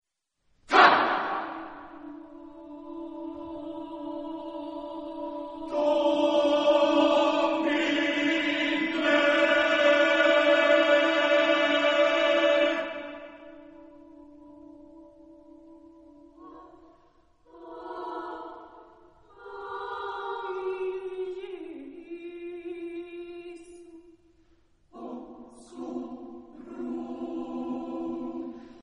Epoque: 20th century  (1980-1989)
Genre-Style-Form: Contemporary ; Sacred ; Requiem
Soloist(s): Mezzosoprano (1)  (1 soloist(s))